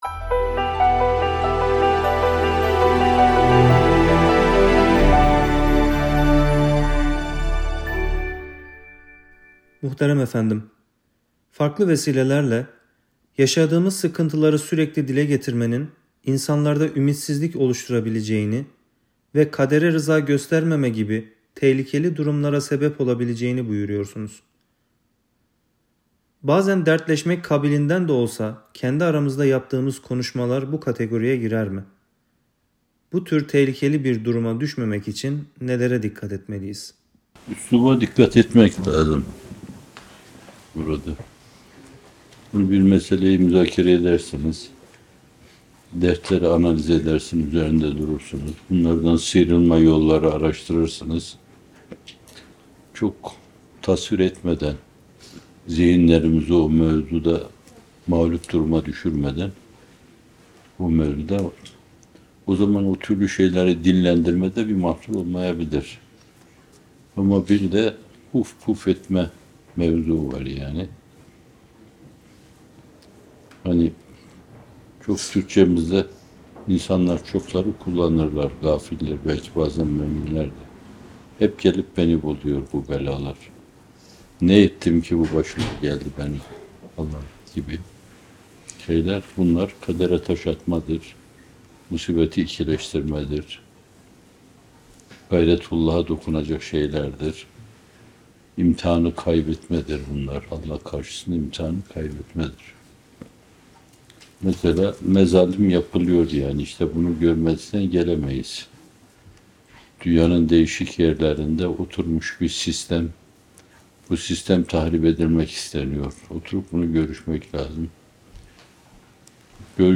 Muhterem Fethullah Gülen Hocaefendinin 9 Şubat 2017 tarihinde yapmış olduğu ilk kez yayınlanan sohbeti.